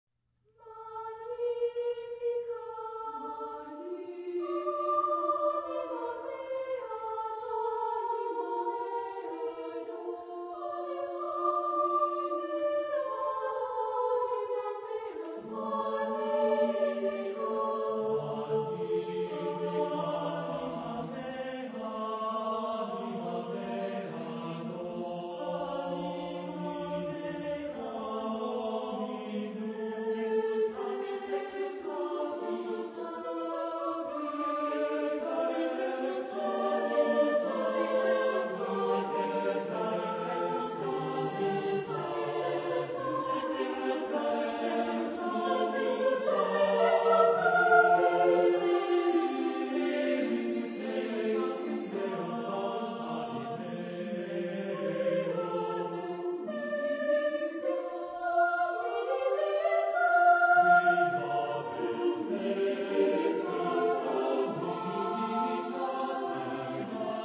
Genre-Style-Forme : Sacré ; Magnificat
Type de choeur : SATB  (4 voix mixtes )
Tonalité : ré mineur